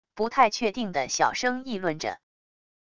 不太确定地小声议论着wav音频